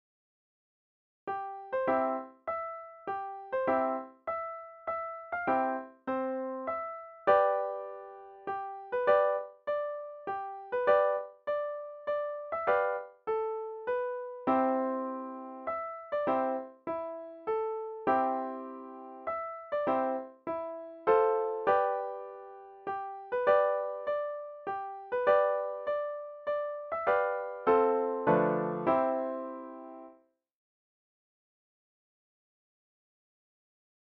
Deense volksmuziek